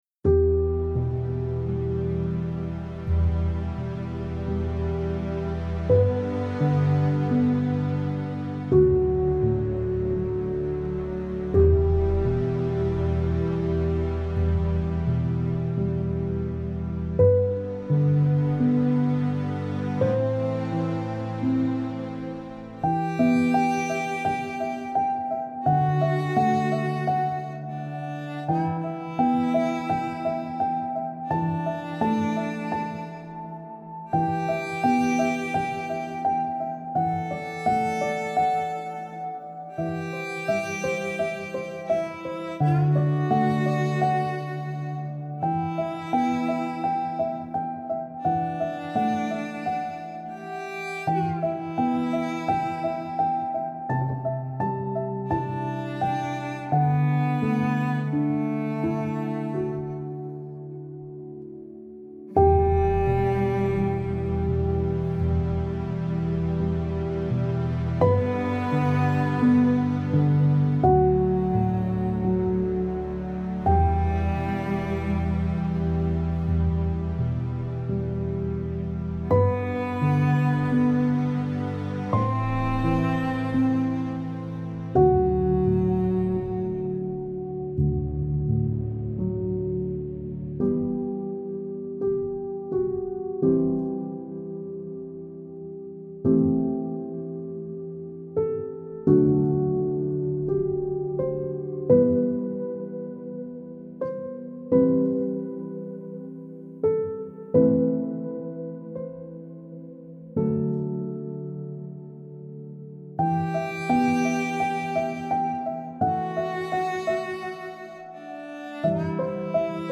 سبک آرامش بخش , پیانو , عاشقانه , عصر جدید , موسیقی بی کلام